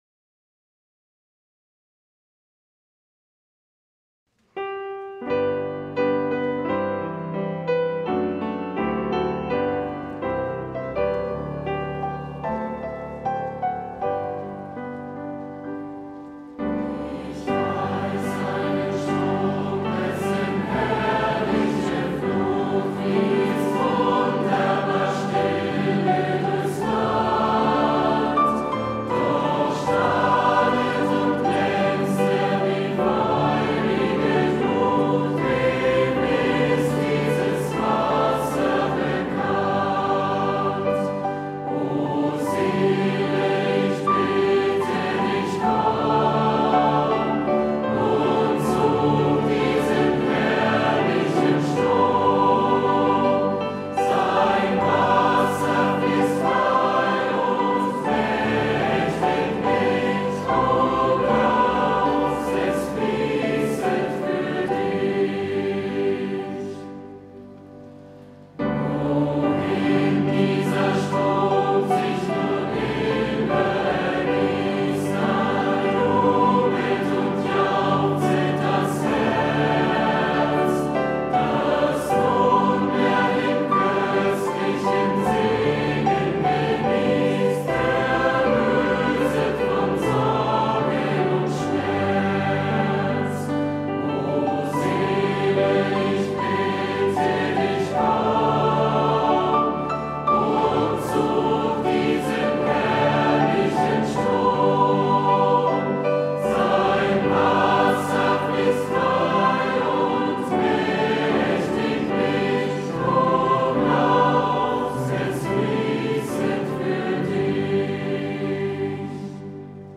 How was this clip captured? Passionssingen 2026